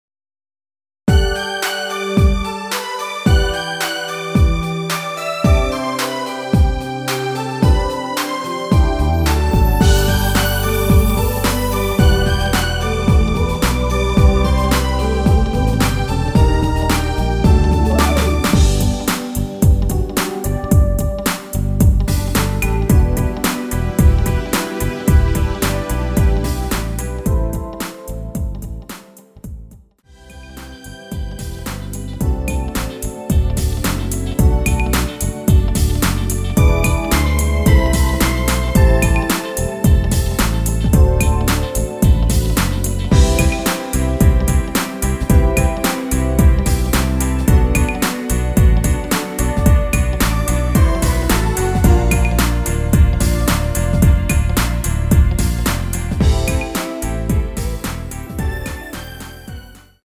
원키(1절+후렴) MR입니다.
앞부분30초, 뒷부분30초씩 편집해서 올려 드리고 있습니다.
중간에 음이 끈어지고 다시 나오는 이유는